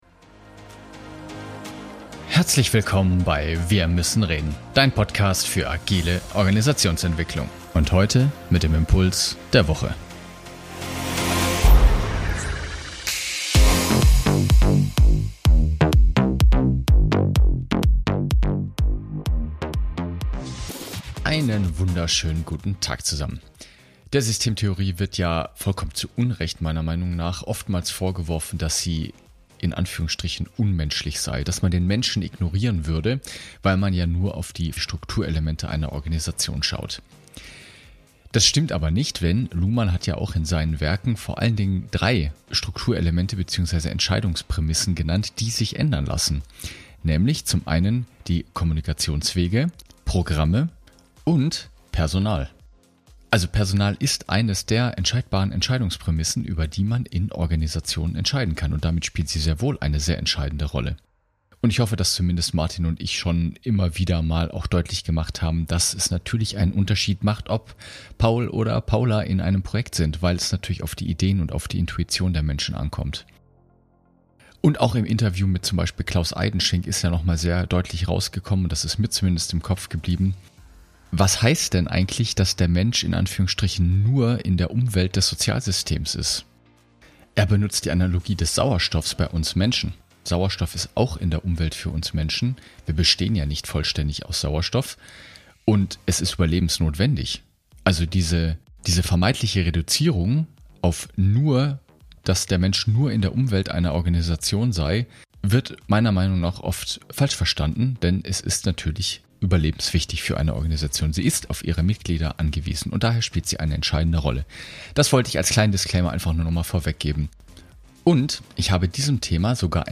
In dieser Folge bekommst du wieder einen Hörprobe, genau zu diesem Thema.